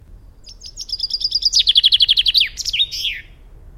Большинство песен зяблика устроено так: вначале идет одна или несколько разных трелей (серий одинаковых нот), а в конце звучит заключительная громкая фраза — «росчерк».
Представьте себя Питером Марлером, послушайте записи песен шести зябликов и подберите для каждой из них трели и «росчерк» из предложенных схем.
chaffinch1.mp3